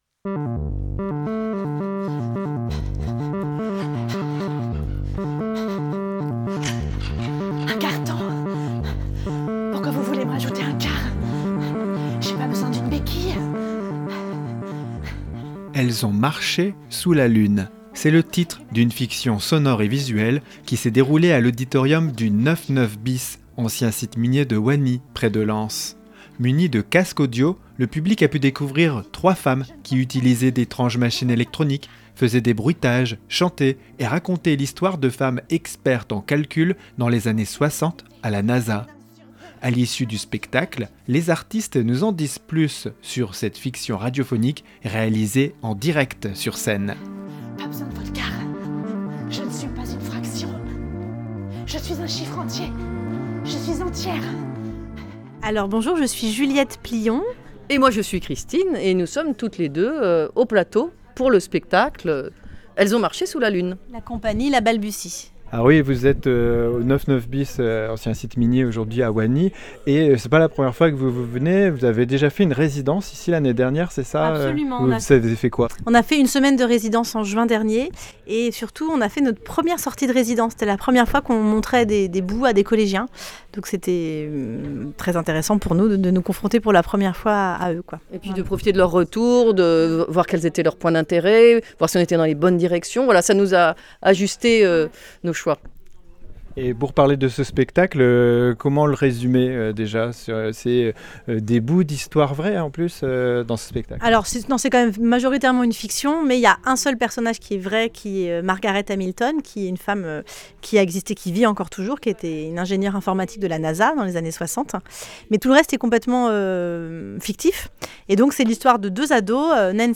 A l’issue du spectacle, les artistes nous en disent plus sur cette fiction radiophonique réalisée en direct sur scène.